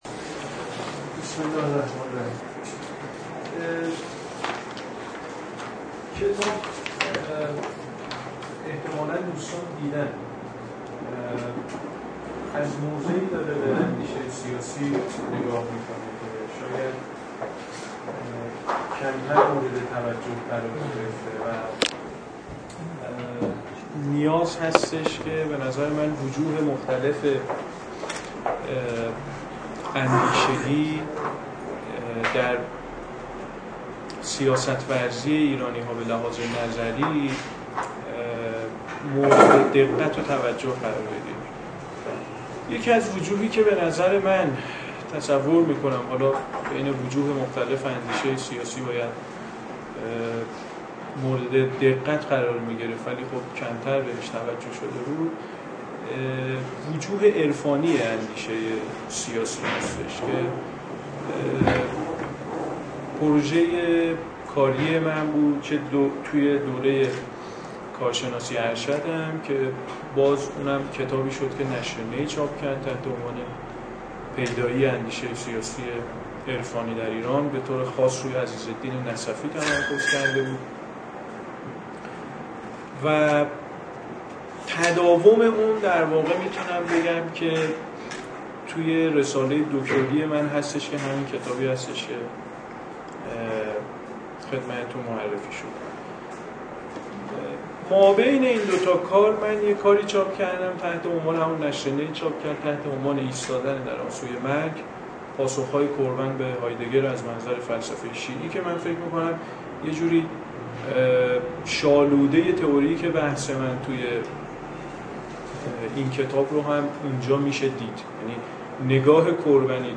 سخنرانی
انجمن علوم سیاسی ایران